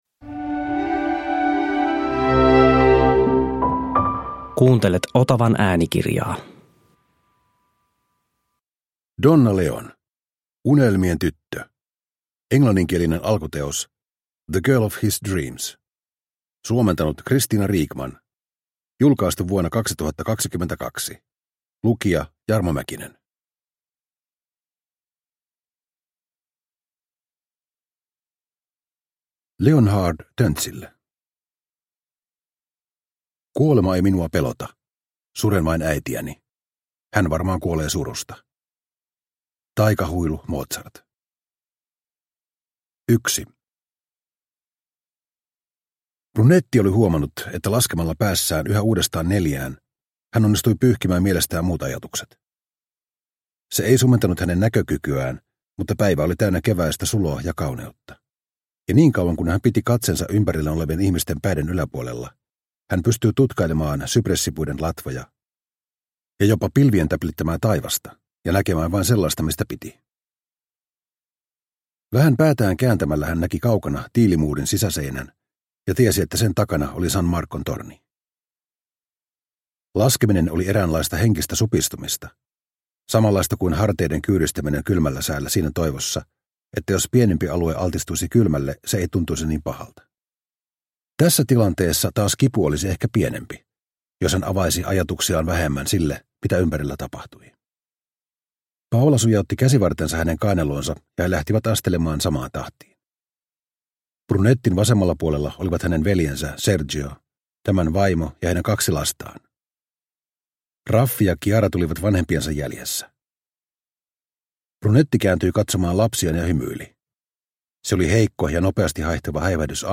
Unelmien tyttö – Ljudbok – Laddas ner